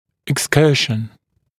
[ɪks’kɜːʃn ] [eks-][икс’кё:шн ] [экс-]отклонение, отступление